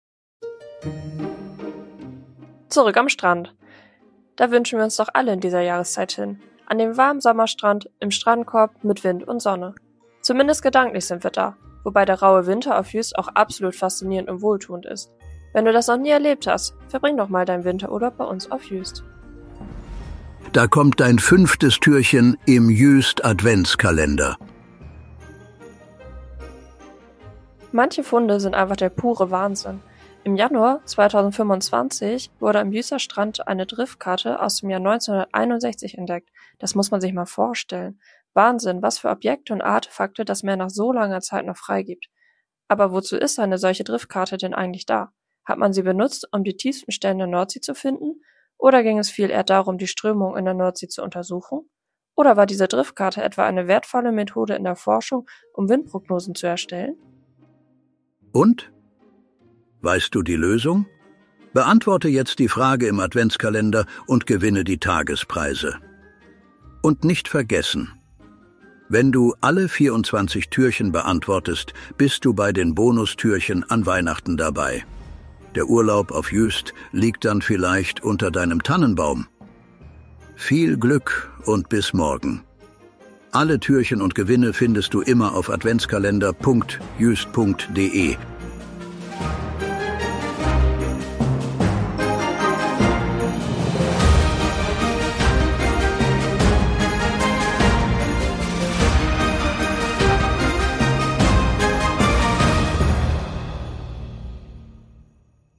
guten Geistern der Insel Juist, die sich am Mikro abwechseln und